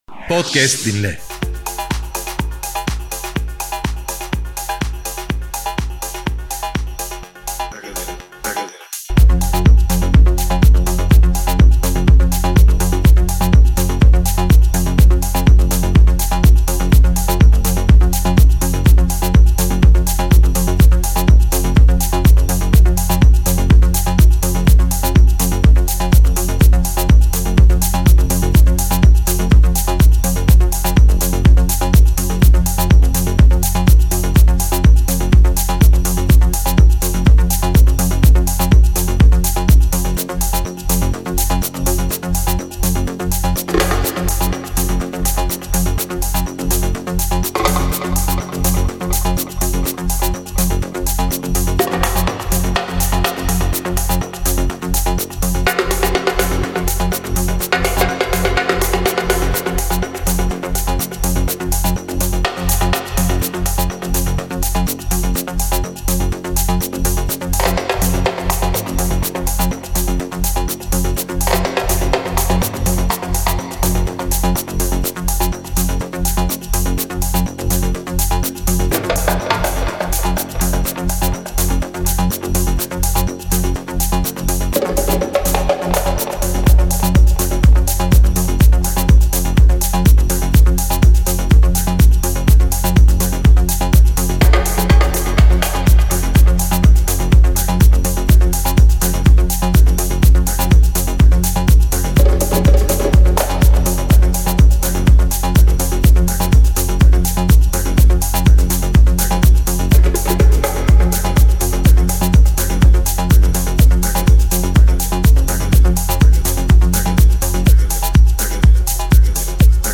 House Set